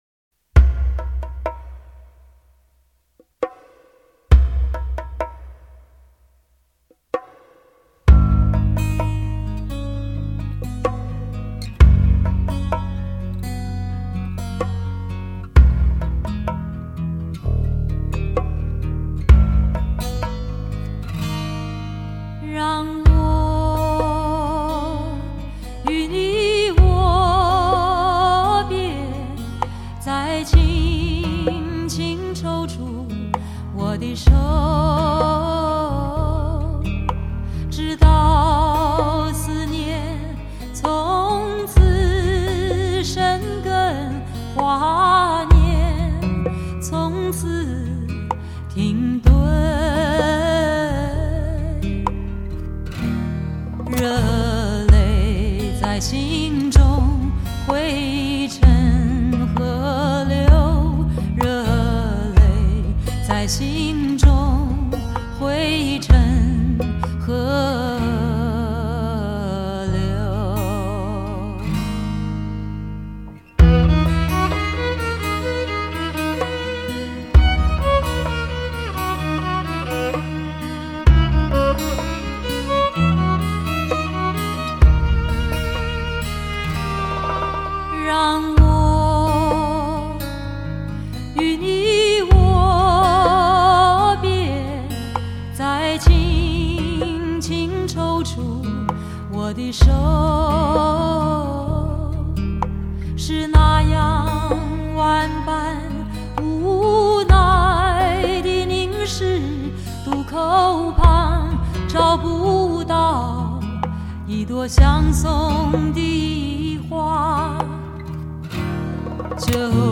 HI-FI顶级人声天碟
发烧友必备人声试音典范之作
高密度24BIT数码录音